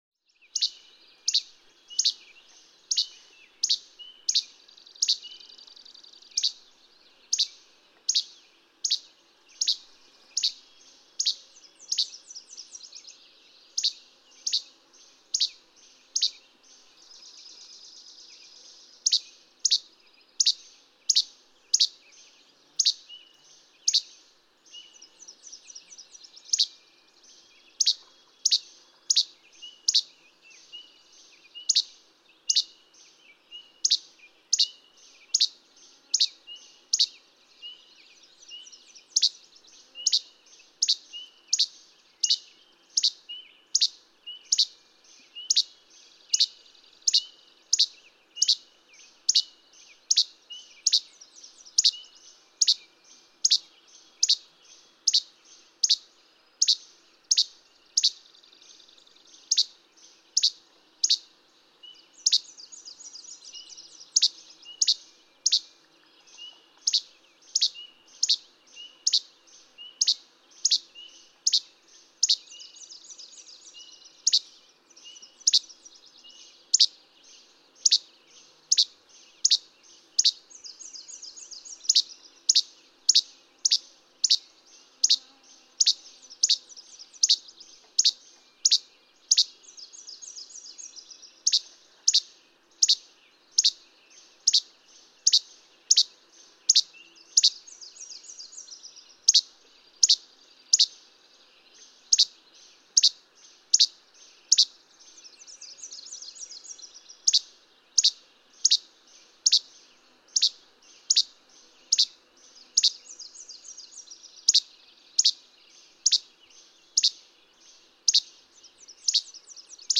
Least flycatcher
Ear Falls, Ontario.
362_Least_Flycatcher.mp3